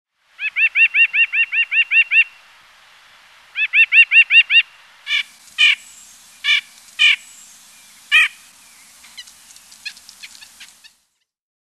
White-breasted Nuthatch
nuthatch.mp3